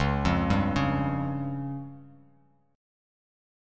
C#Mb5 chord